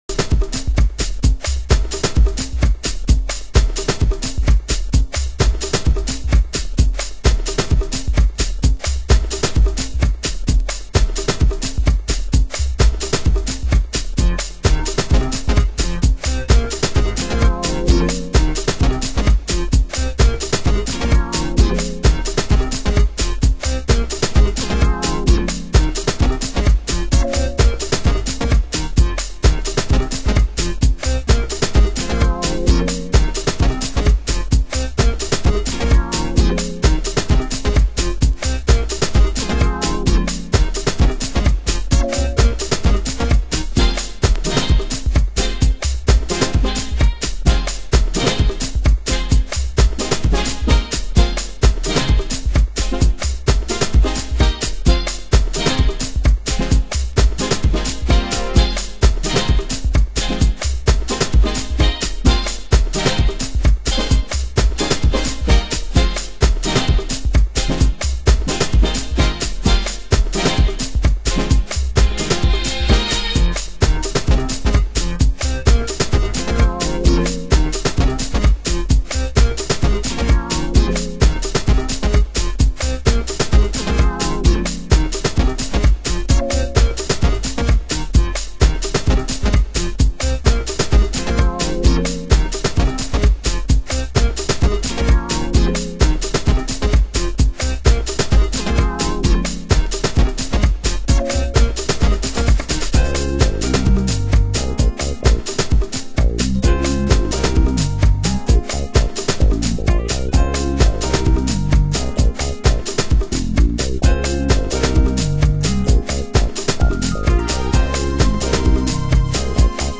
盤質：小傷有/少しチリパチノイズ有 　　ジャケ：A4インフォ付き